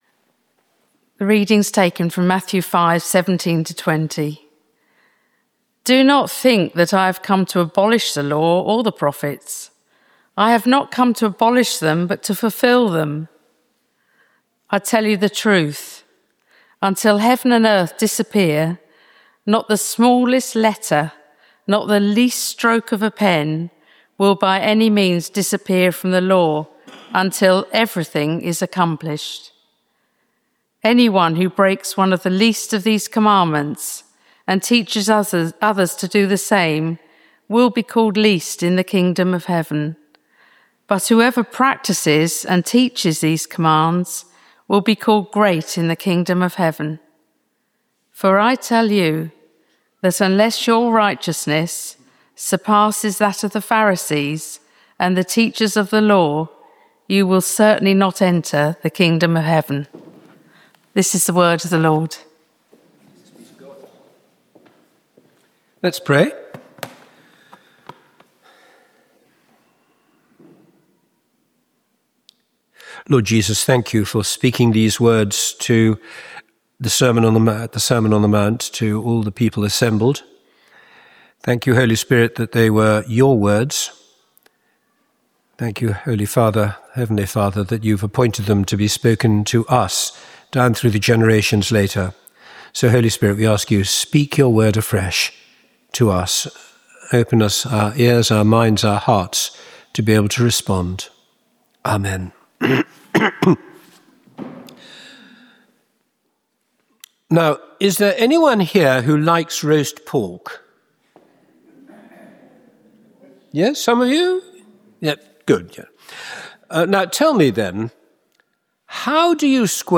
Location: St Mary’s, Slaugham Date
Service Type: Morning Worship